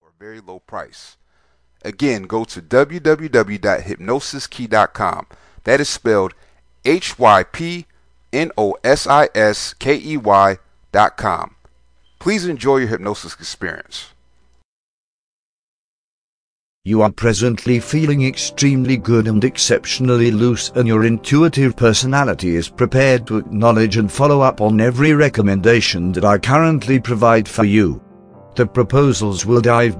Welcome to Binge Drinking Hypnosis, this is a powerful hypnosis script that helps you stop binge drinking.